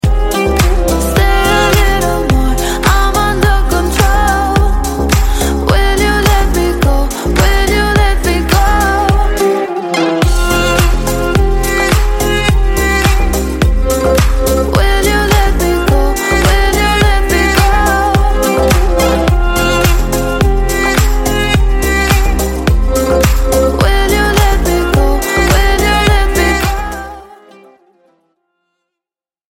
Клубные Рингтоны » # Восточные Рингтоны
Танцевальные Рингтоны